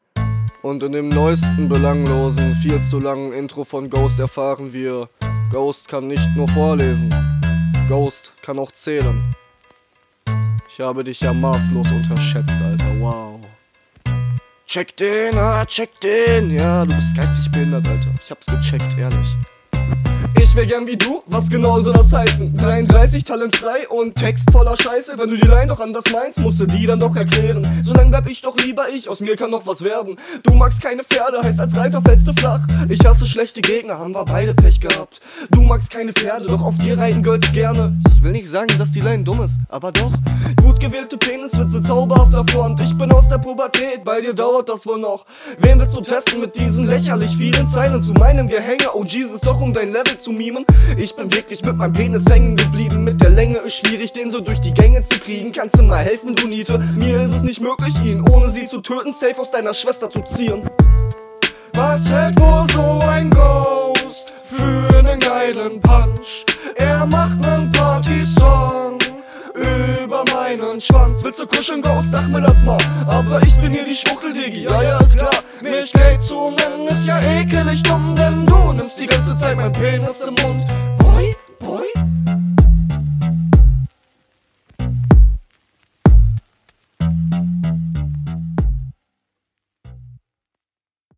Stimme auch hier nicht so geil.